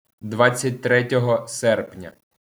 Аудиофайл с произношением.